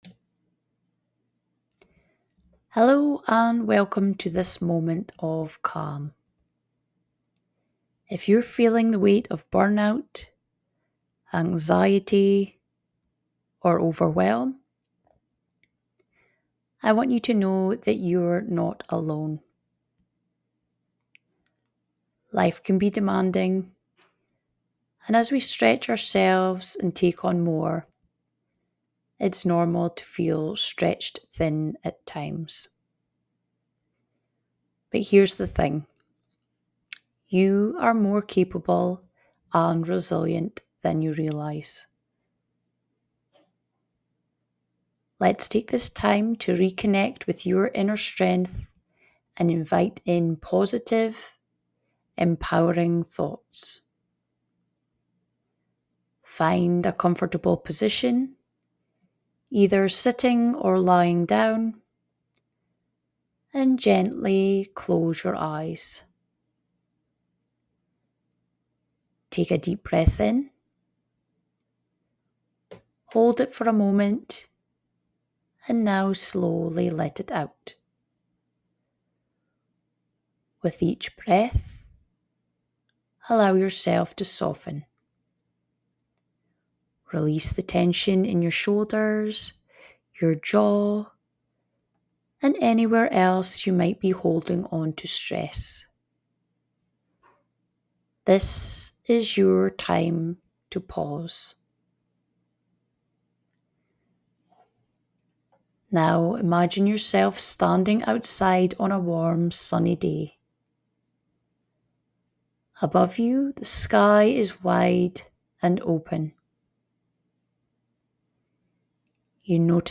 In this episode, we take a mindful pause to address burnout, anxiety, and overwhelm. Through a soothing meditation and empowering affirmations, you'll reconnect with your inner strength and build resilience for life's challenges.